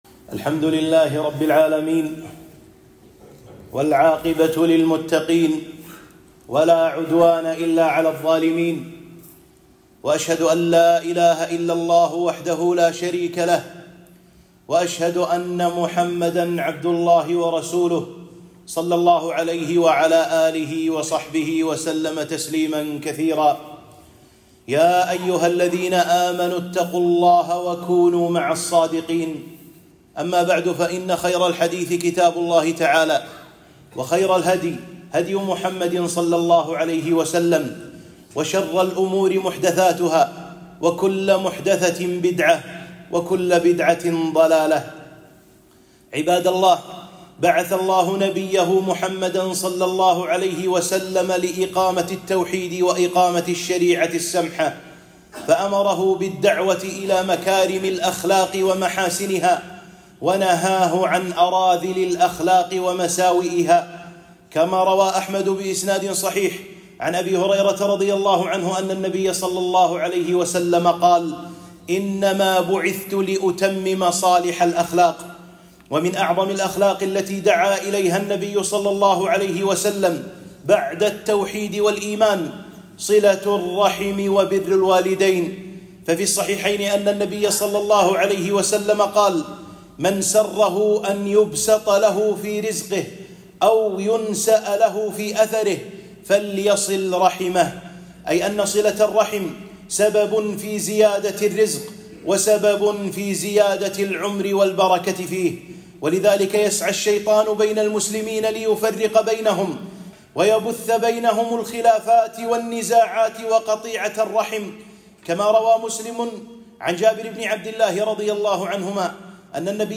خطبة - صلة الرحم، وموسم الاختبارات الفصلية